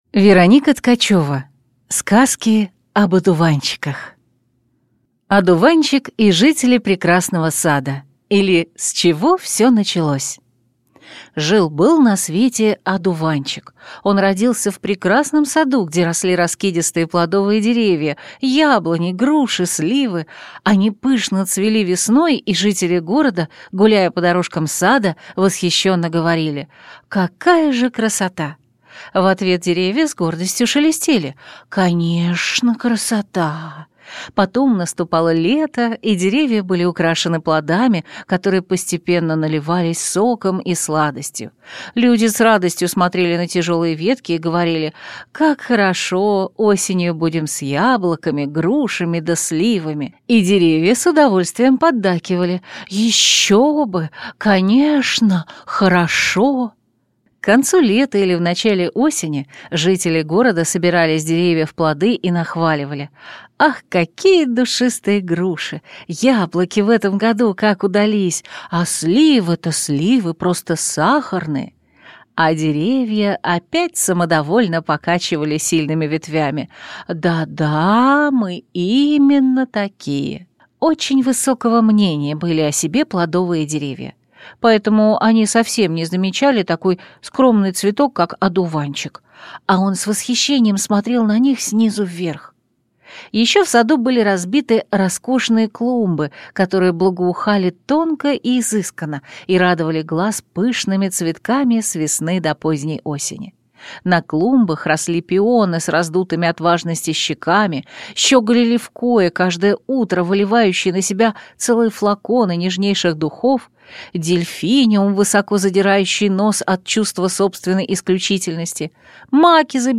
Аудиокнига Сказки об Одуванчиках | Библиотека аудиокниг